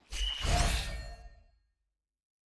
unlock.ogg